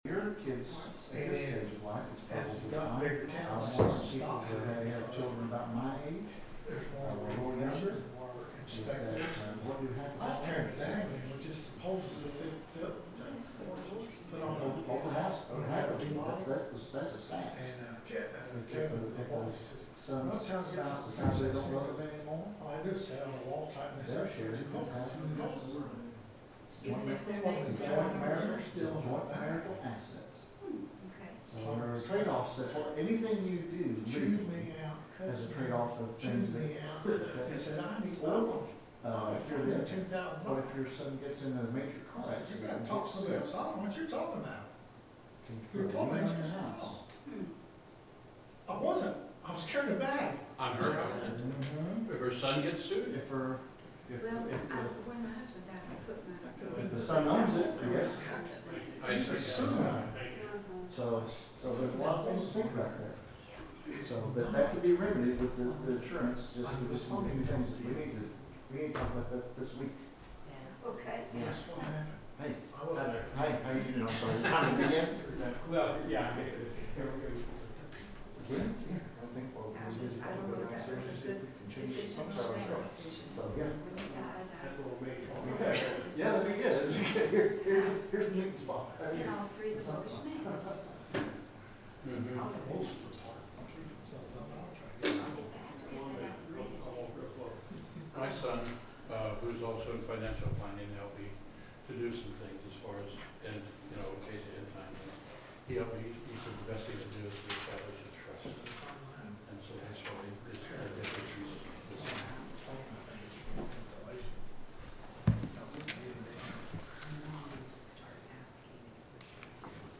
The sermon is from our live stream on 8/24/2025